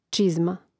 čȉzma čizma im. ž. (G čȉzmē, DL čȉzmi, I čȉzmōm; mn.